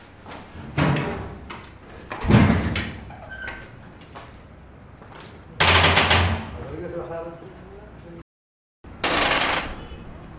加沙 " 在空旷的混凝土房间里，门是金属的，吱吱嘎嘎地开着，从里面传来阵阵声响。
描述：门金属大吱吱嘎吱打开外面从空混凝土房间施工现场听到bg noise.wav